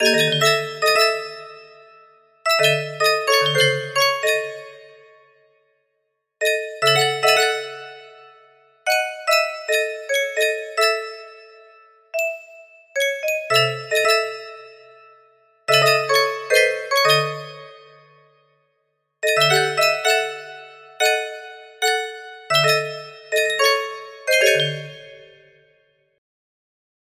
1 music box melody
Full range 60